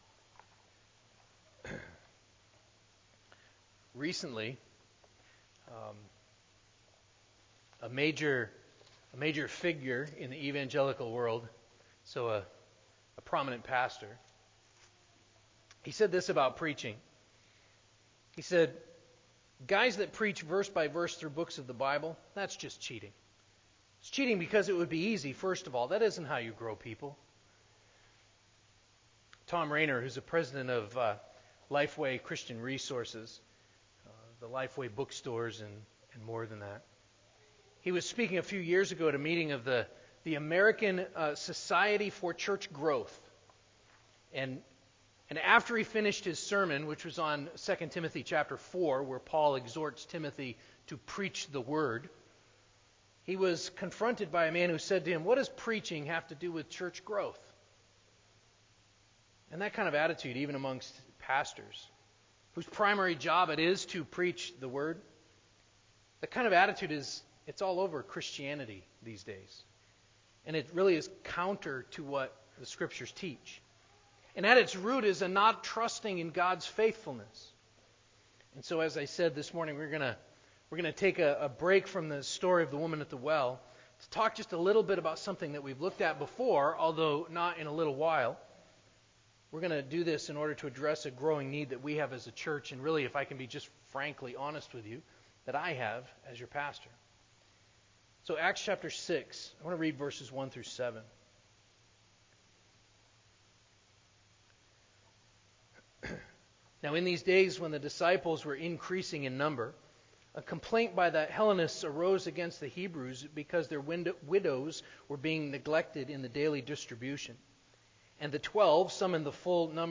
Stand Alone Sermon